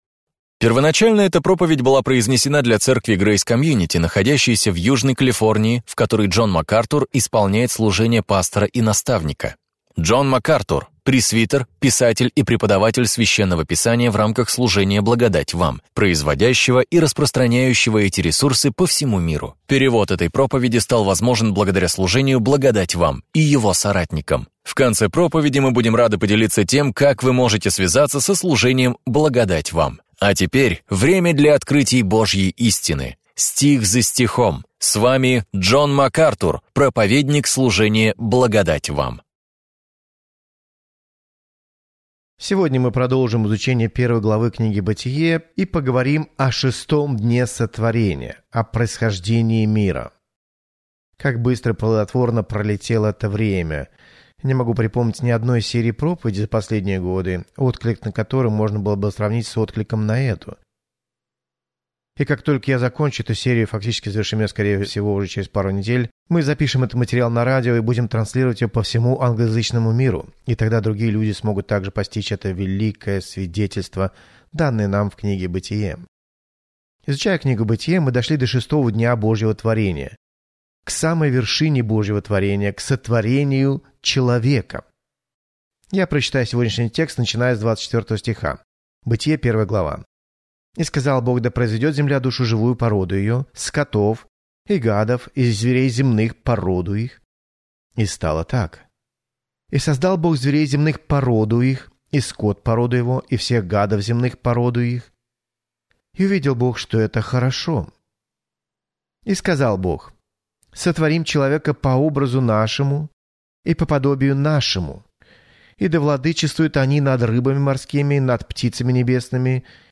Проповеди МакАртура